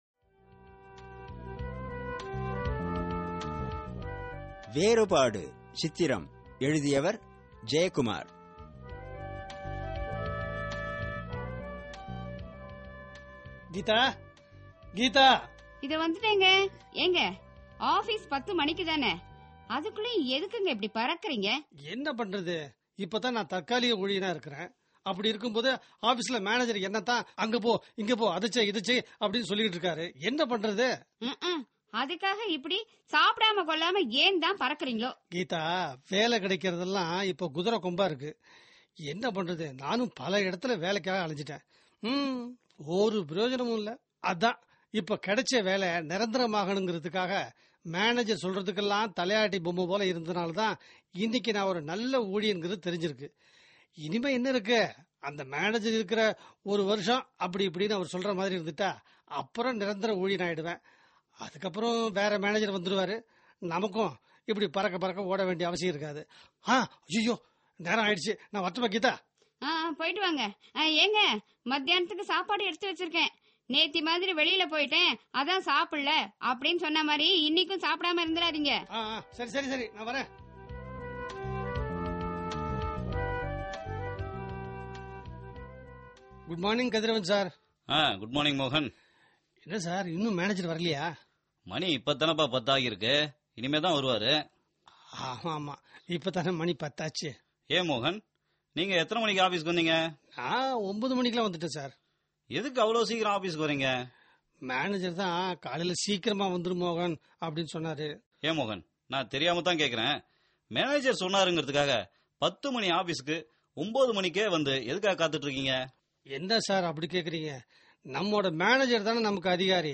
Directory Listing of mp3files/Tamil/Dramas/Social Drama/ (Tamil Archive)